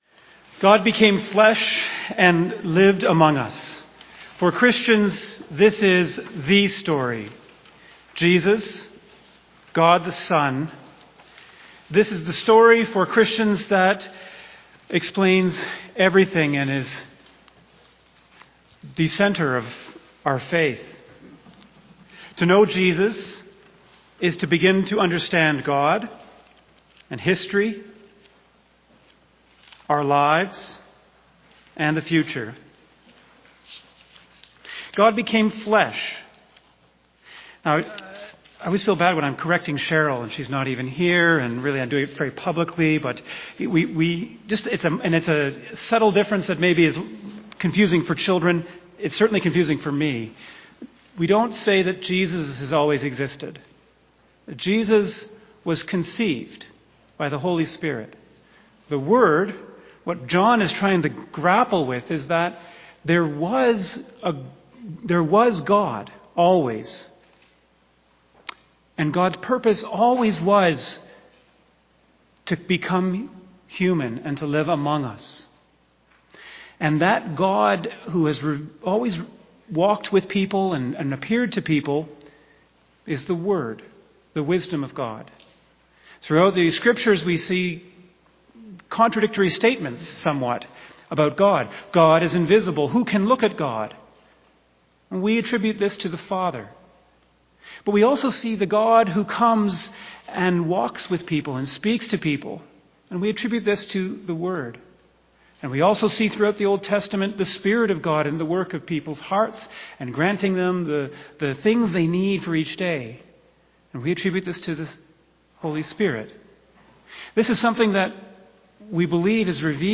2025 Sermon October 19 2025